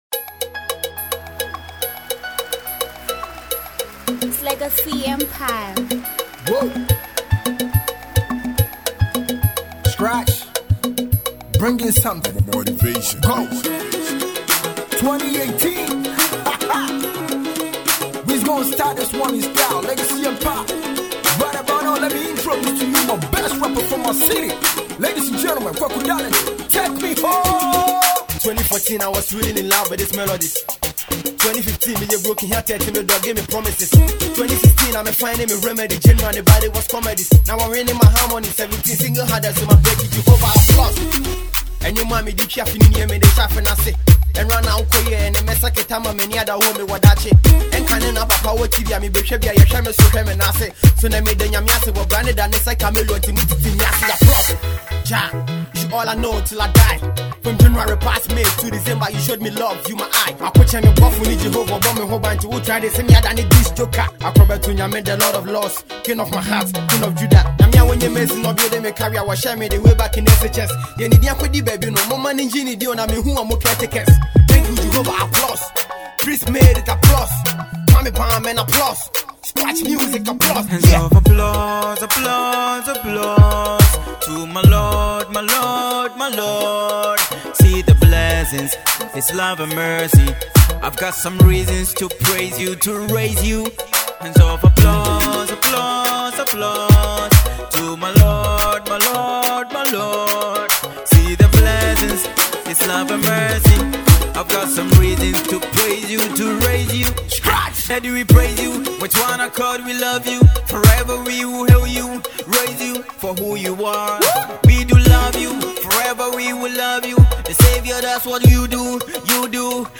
thanksgiving song